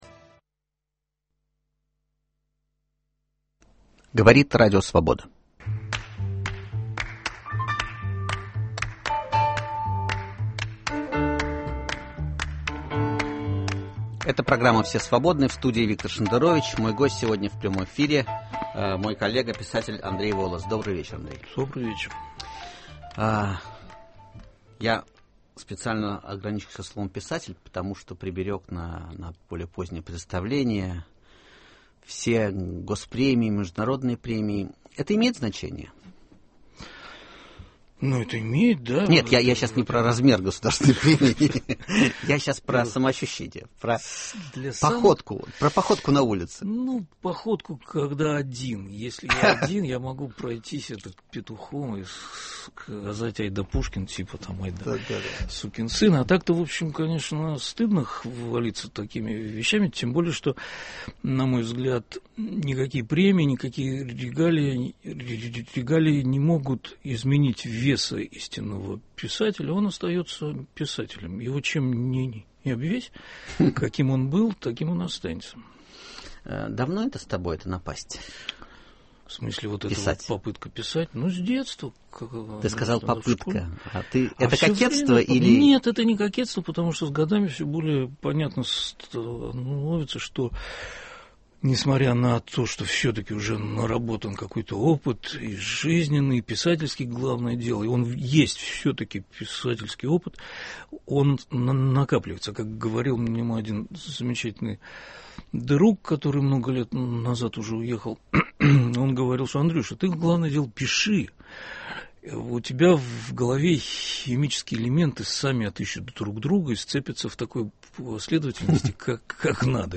В гостях у Виктора Шендеровича – Финалист национальной литературной премии «Большая книга», прозаик Андрей Волос.